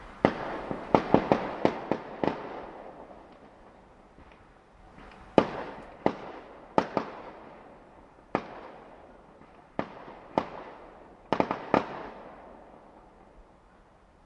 烟花现场记录
描述：我的H1 Zoom记录了一些烟花氛围。但是你认为合适。
Tag: 烟花 周围环境 录音